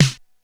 Index of /90_sSampleCDs/300 Drum Machines/Korg DSS-1/Drums01/03
Snare.wav